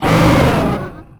horror
Dragon Hurt Roar 9